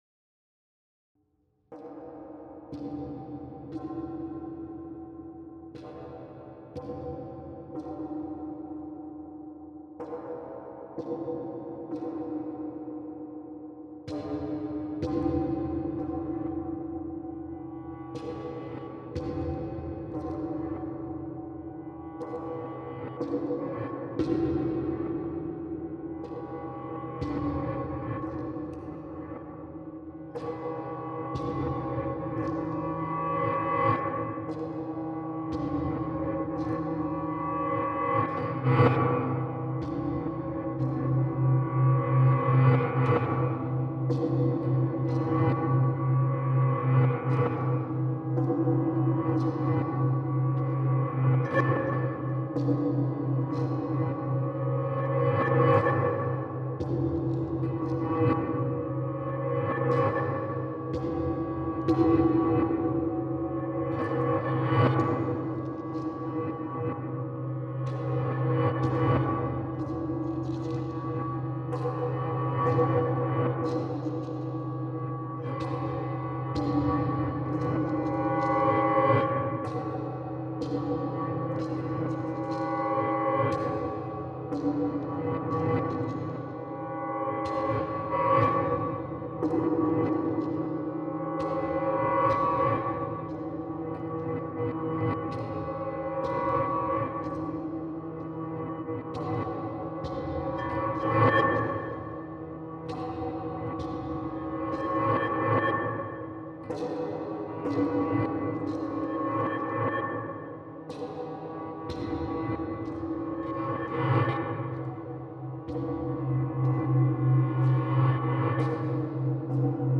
• Genre: Industrial / Esoteric / Dark Ambient